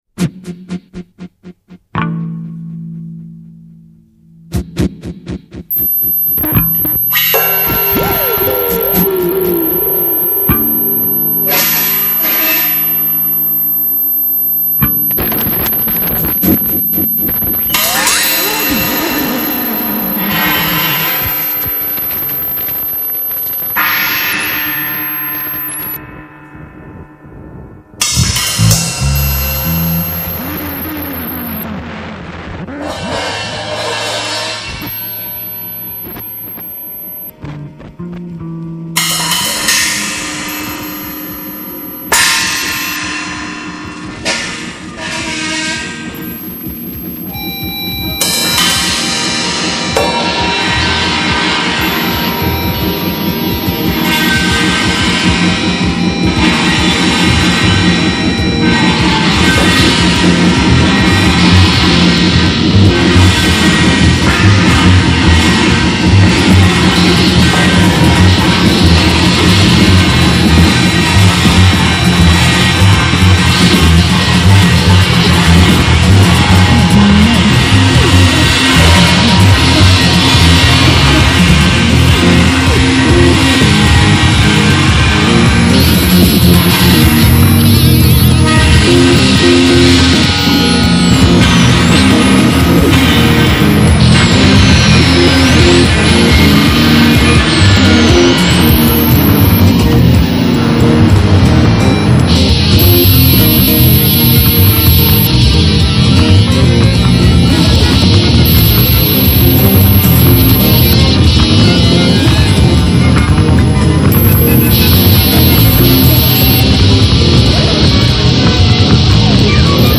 Enregistré et mixé au studio CCAM